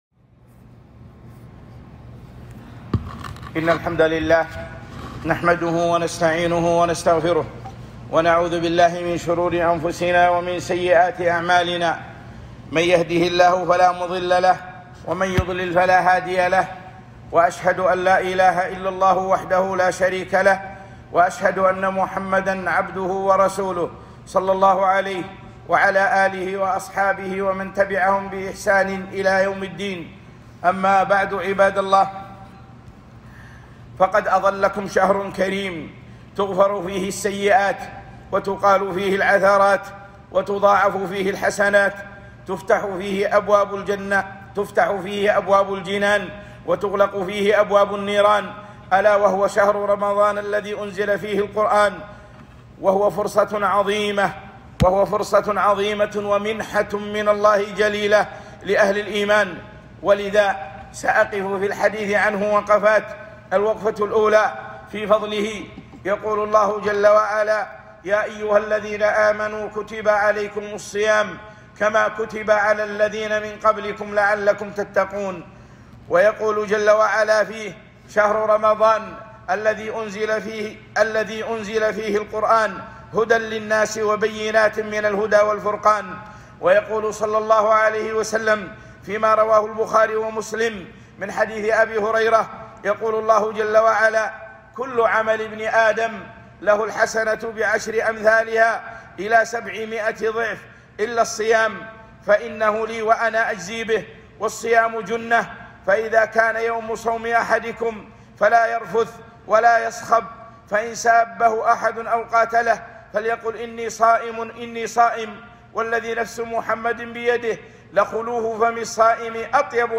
خطبة - استقبال رمضان 1443